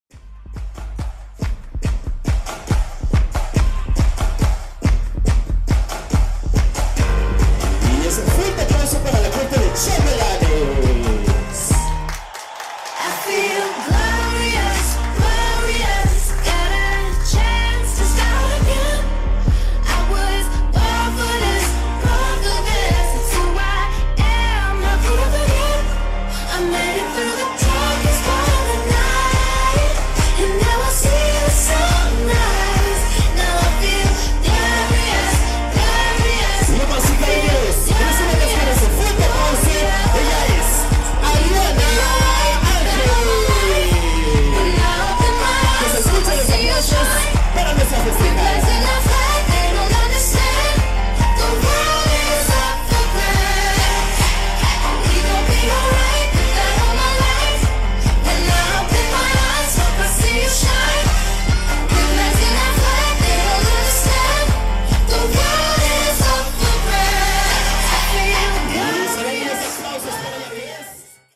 de la música Pop